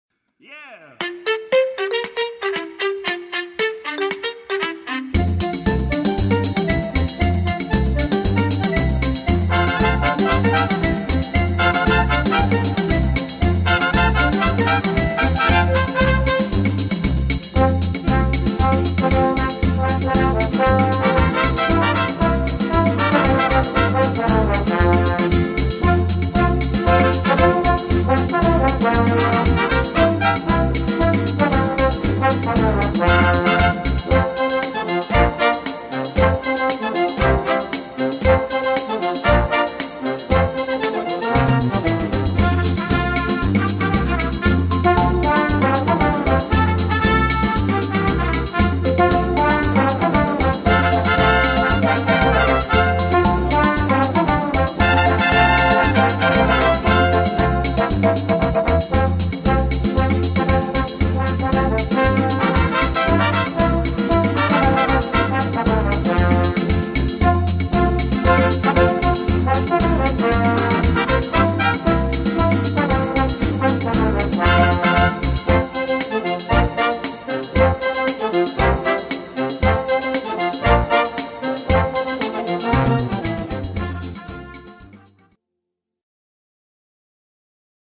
Gattung: Unterhaltungsmusik
Besetzung: Blasorchester